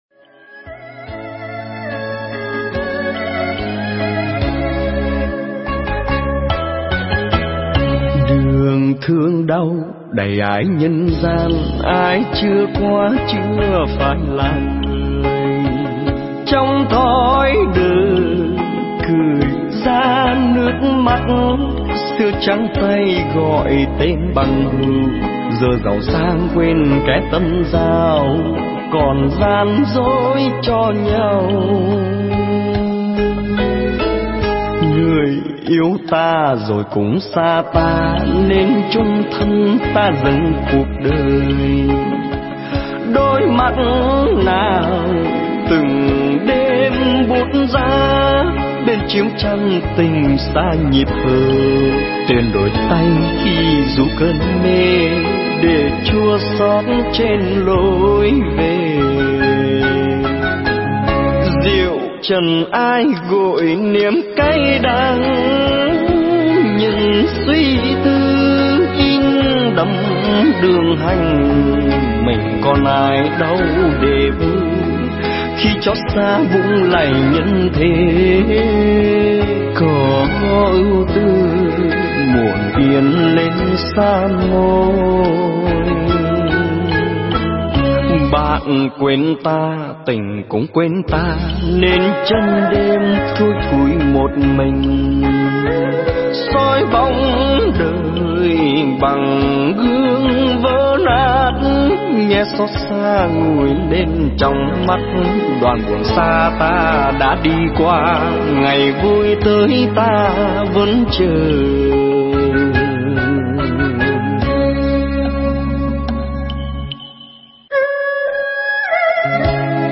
Nghe Mp3 thuyết pháp Nặng Nợ Hồng Trần
tại Chùa Phổ Hiền, Chicago, Mỹ